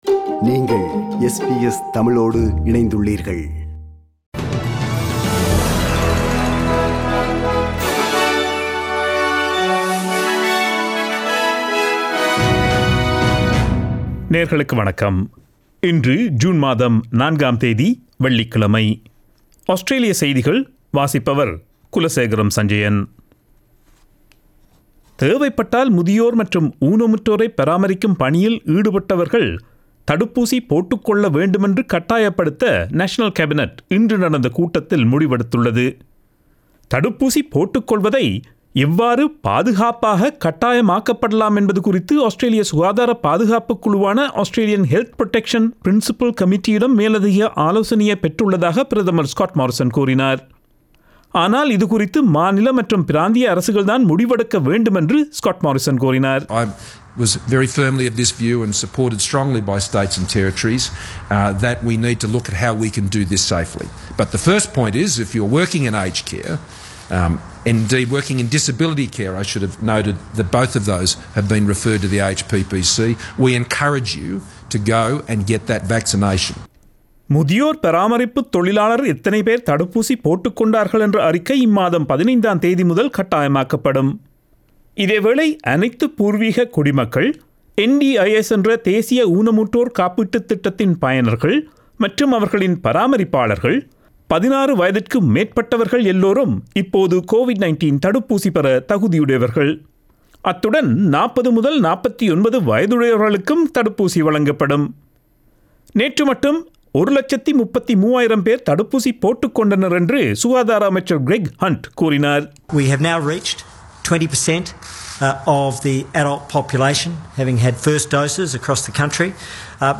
Australian news bulletin for Friday 04 June 2021.